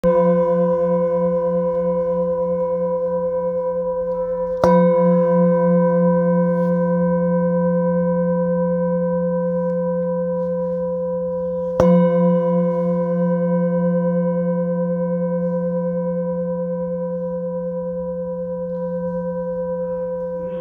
Handmade Singing Bowls-31753
Singing Bowl, Buddhist Hand Beaten, with Fine Etching Carving of Chenrezig, Select Accessories
Material Seven Bronze Metal